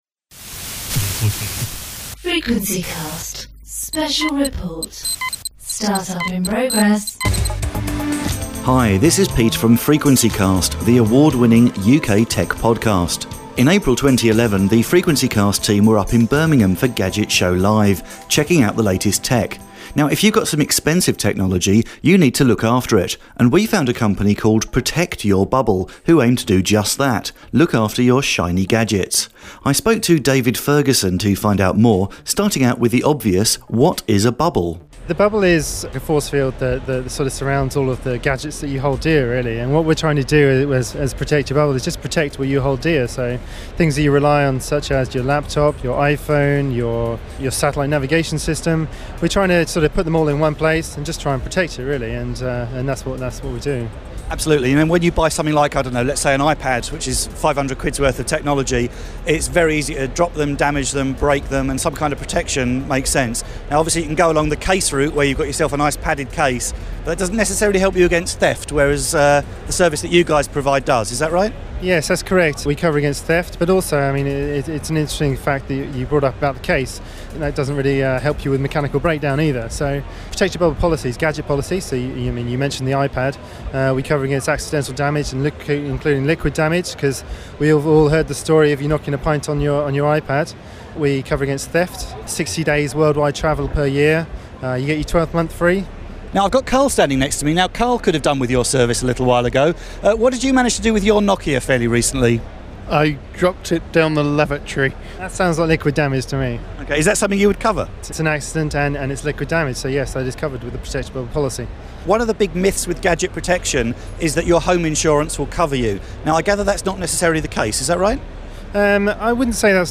Replacing your gadgets in the event of damage, loss or theft is not going to be cheap. As part of our coverage of Gadget Show Live 2011, we were able to meet up with Protect Your Bubble, a firm that offer gadget insurance to protect your precious tech.